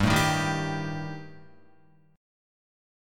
Gsus2#5 chord